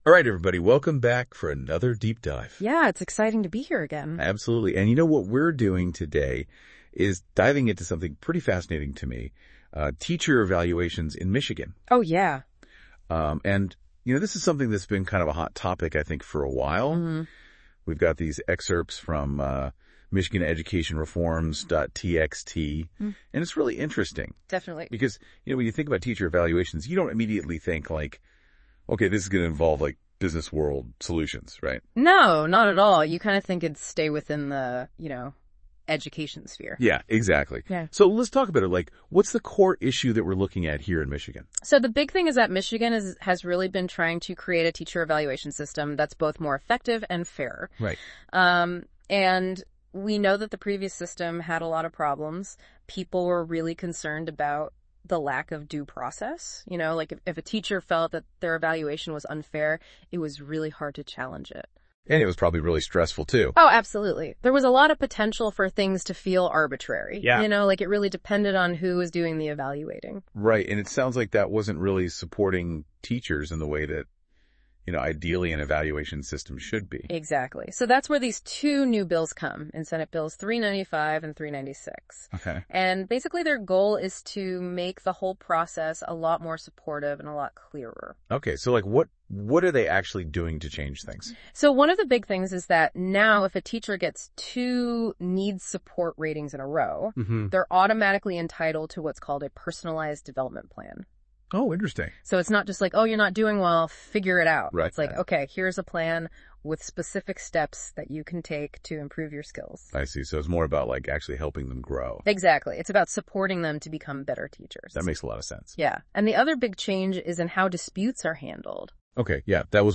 This tool transforms written content into dynamic audio discussions, where AI hosts summarize key insights, link related topics and create an engaging conversation.
Click here to listen to the AI-generated podcast summary of this article